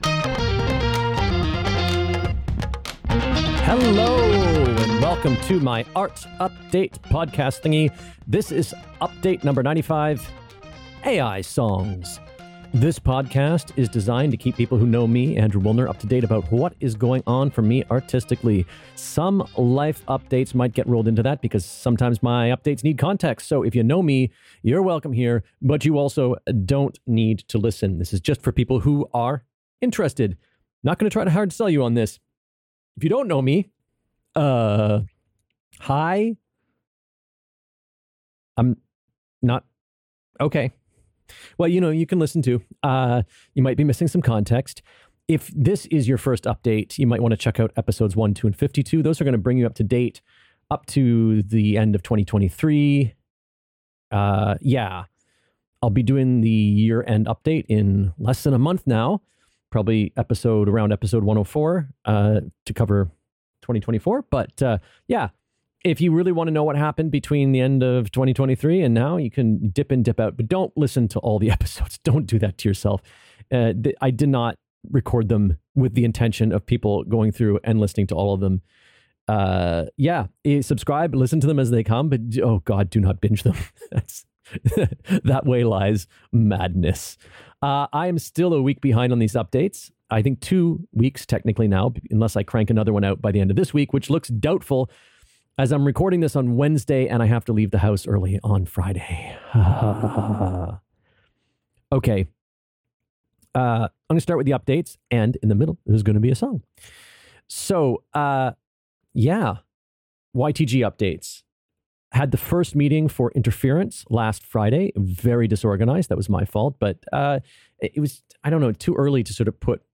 You can hear how it messes a few things up; this was the best of four versions I created Actually grabbed the intro from another version because it was clearer